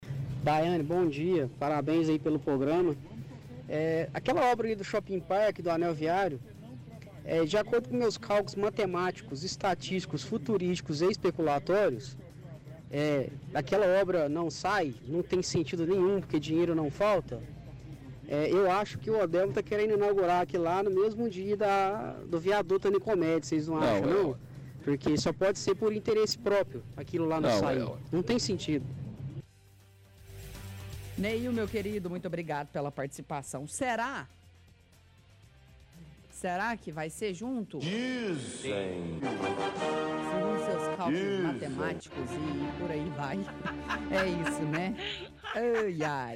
– Ouvinte reclama de demora de obra do anel viário e diz achar que o prefeito Odelmo quer inaugurar a mesma época de obra do viaduto da av. Nicomedes, por interesse próprio.